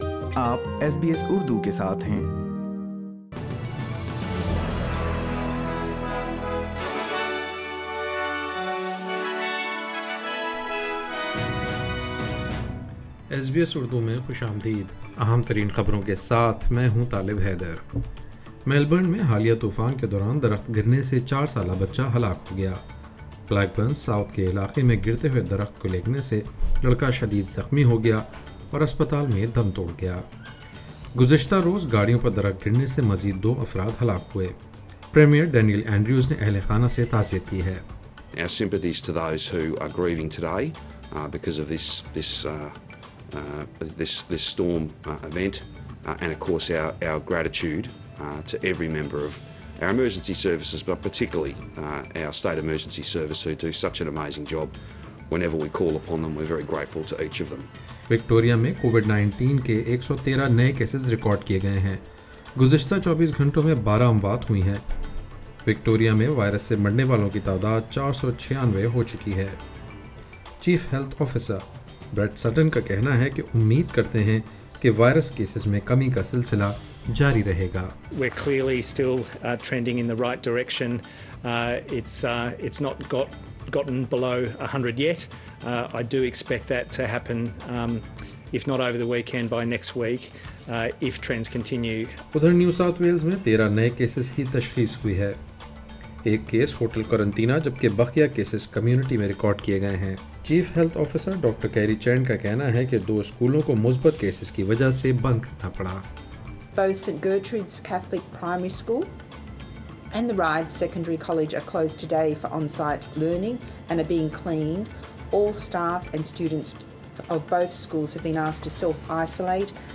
ایس بی ایس اردو خبریں 28 اگست 2020